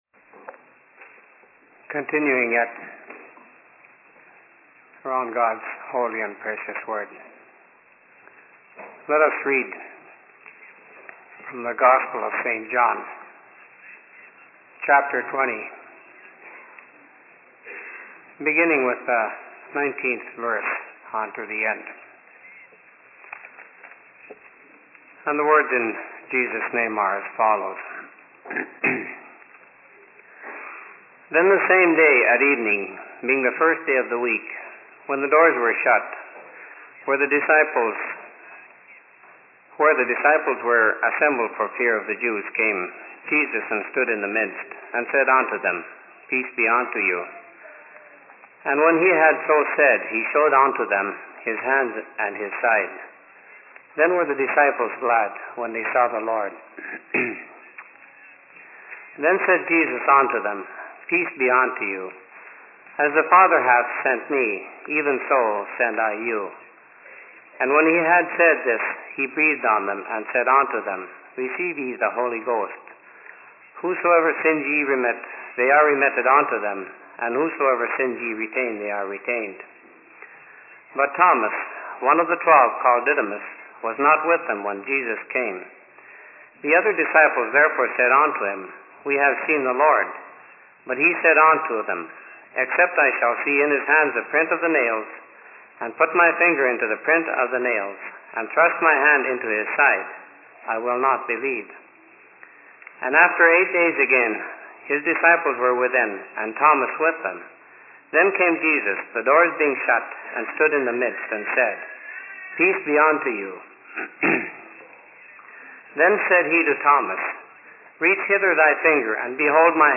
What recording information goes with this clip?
Location: LLC Minneapolis